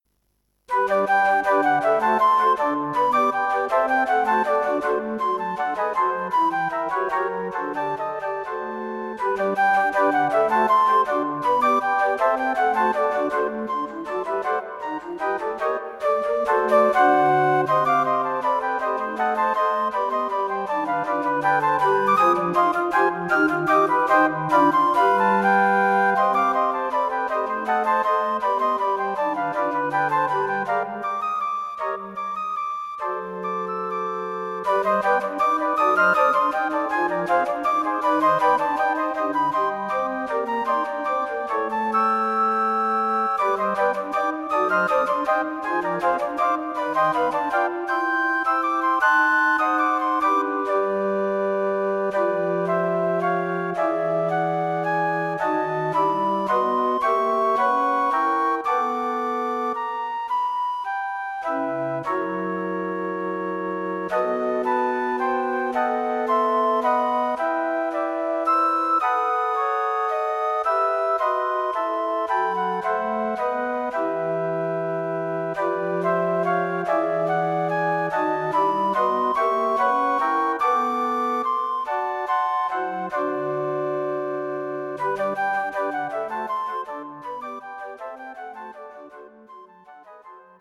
Flute Quartet for Concert performance
The main themes have Twenty-One beats in them.
Flute Quartet - Flute, Flute, Alto Flute, Bass Flute.